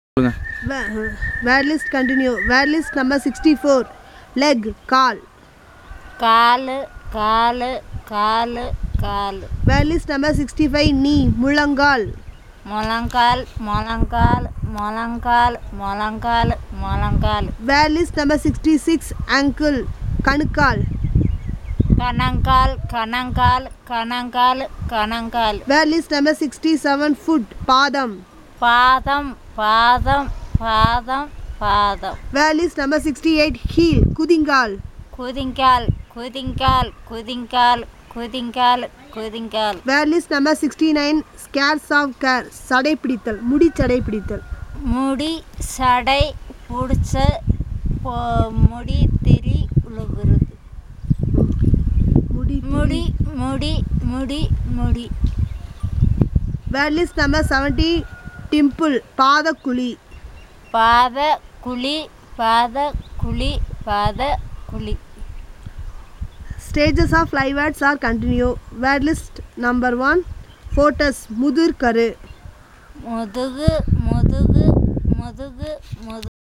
NotesThis is an elicitation of words related to human body parts, using the SPPEL Language Documentation Handbook.